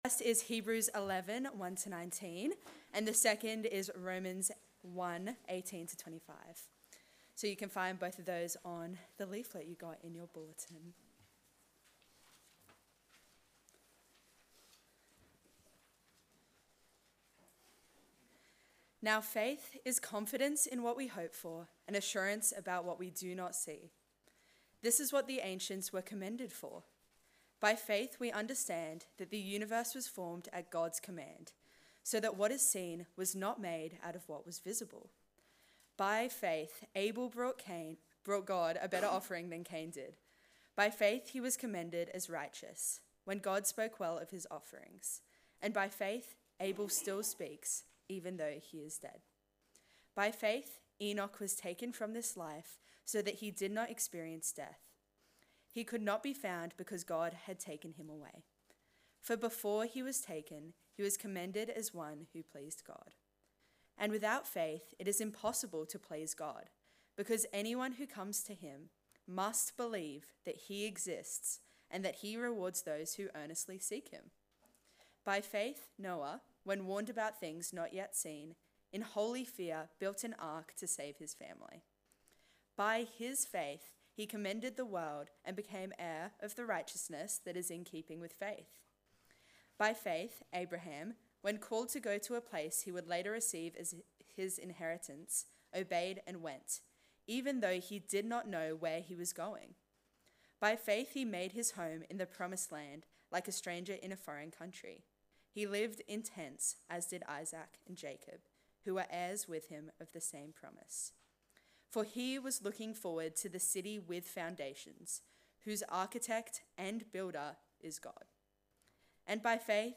Sermon: We Believe in God